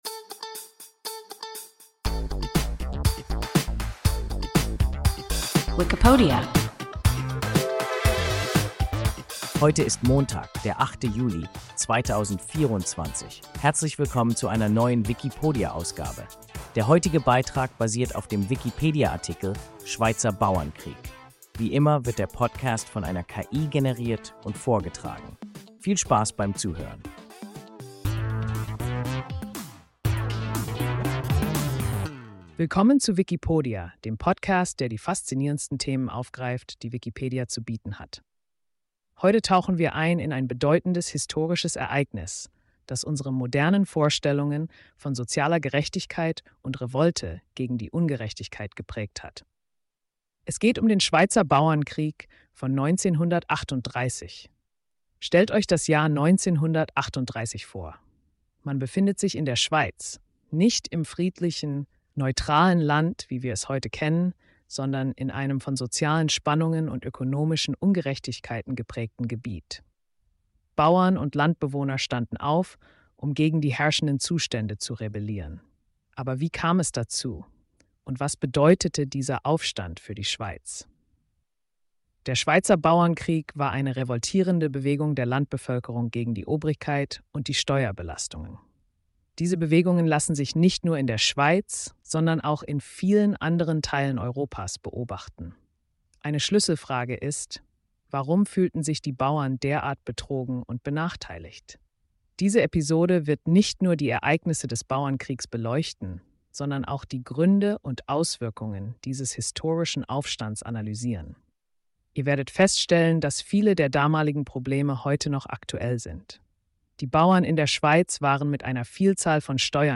Schweizer Bauernkrieg – WIKIPODIA – ein KI Podcast